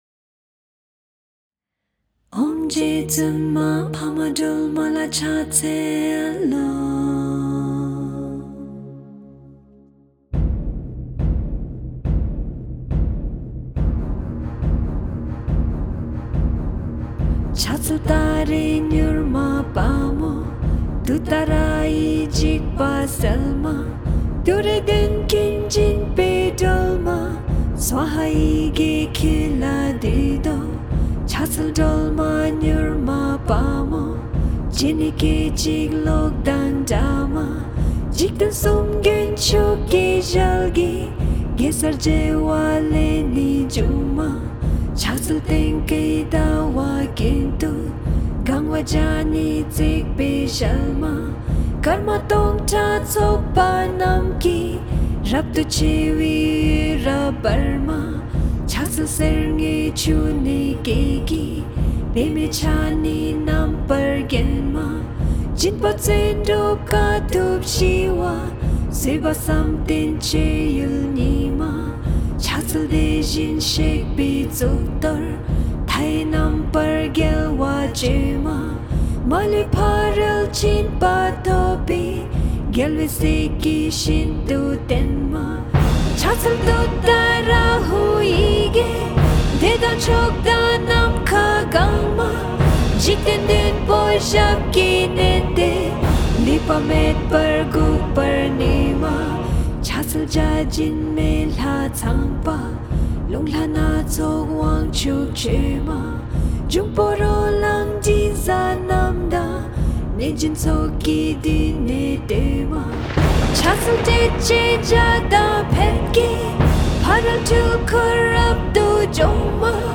21+Tara+Orchestral.mp3